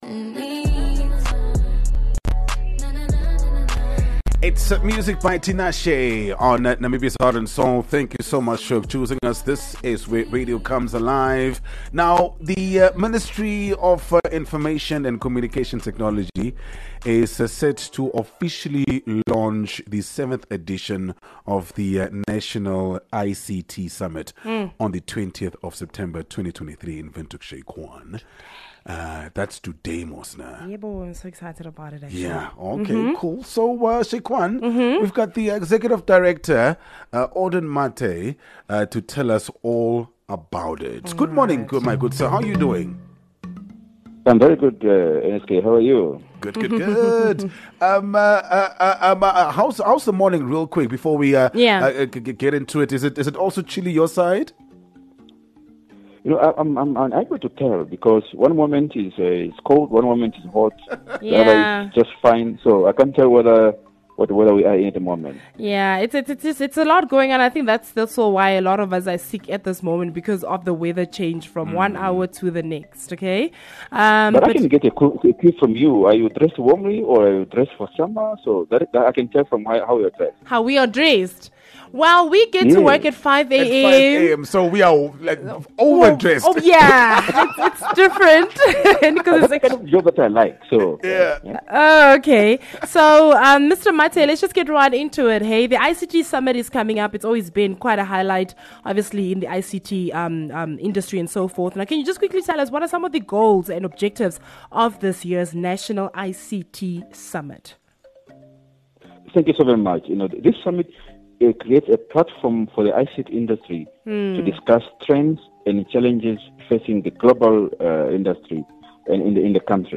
20 Sep Team discusses the 7th edition of the National ICT Summit